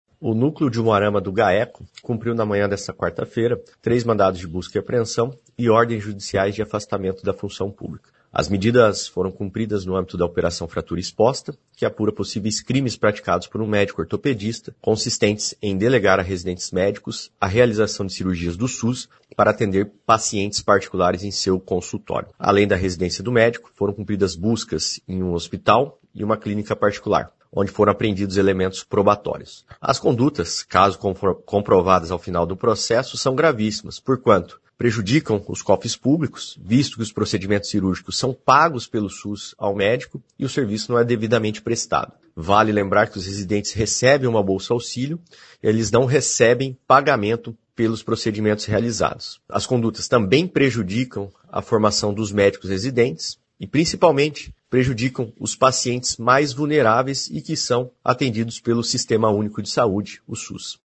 Ouça o que diz o promotor de Justiça, Guilherme Franchi da Silva Santos.